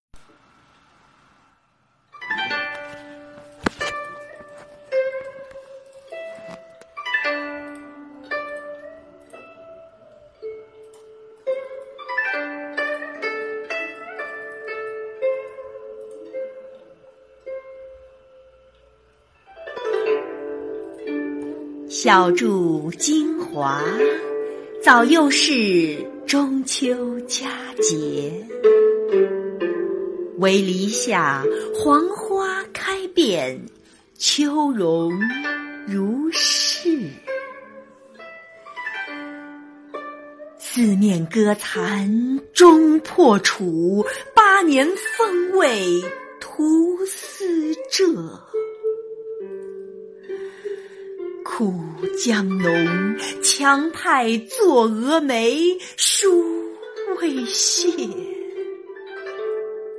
九年级语文下册12词四首《满江红·小住京华》男声配乐朗诵（音频素材）